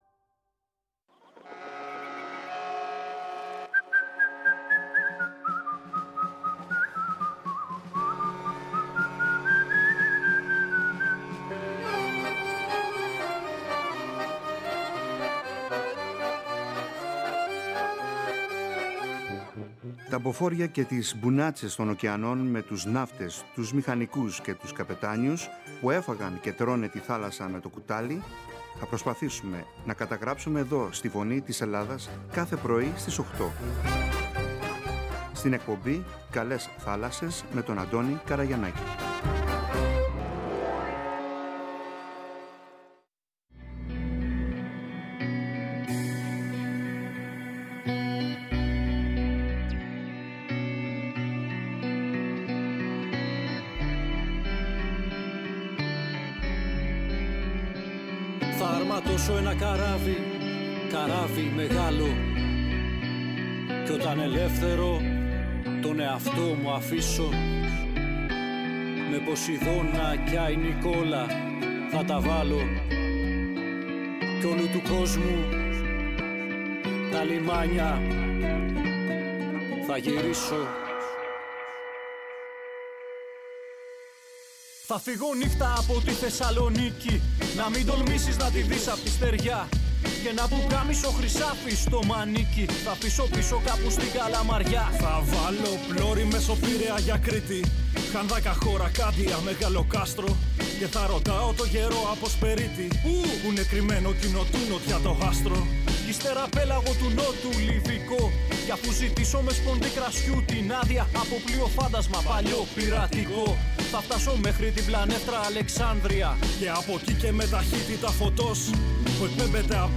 Είναι πιο πολύ ανθρωποκεντρική εκπομπή για τον ναυτικό, με τα θέματα του, τη ναυτιλία, τραγούδια, ιστορίες, συναισθήματα, σκέψεις, και άλλα πολλά όπως π.χ η γυναίκα εργαζόμενη στη ναυτιλία, η γυναίκα ναυτικού, είδη καραβιών, ιστορίες ναυτικών οικογενειών, ιστορίες ναυτικών, επικοινωνία μέσω του ραδιοφώνου, ναυτικές ορολογίες, τραγούδια, ποίηση, πεζογραφία για τη θάλασσα, εξαρτήματα του πλοίου, ήδη καραβιών ναυτικά επαγγέλματα κλπ κλπ Ήδη έχουν ανταποκριθεί αρκετοί, παλιοί και εν ενεργεία καπετάνιοι και μίλησαν με μεγάλη χαρά και συγκίνηση για την ζωή – καριέρα τους στην θάλασσα και τι σημαίνει για αυτούς.